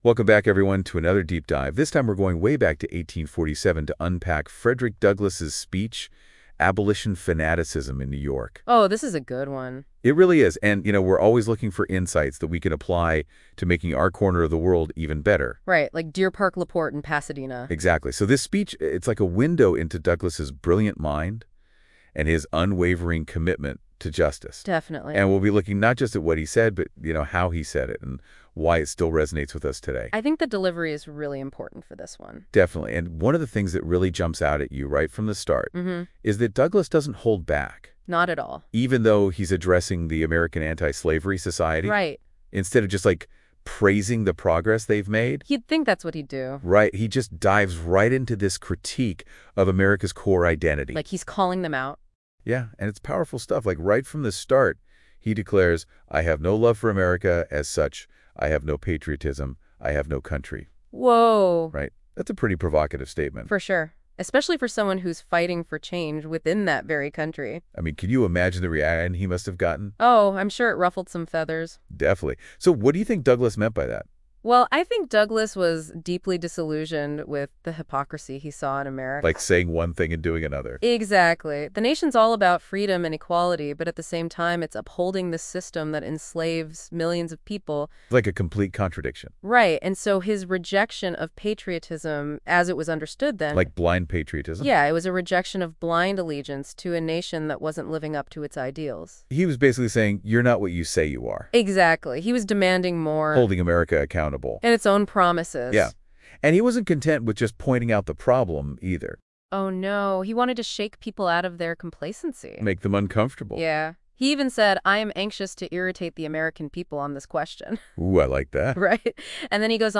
Frederick Douglass's Abolition Speech, New York, 1847 #255 - Think And Act Locally